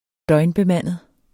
Udtale [ ˈdʌjnbeˌmanˀəð ]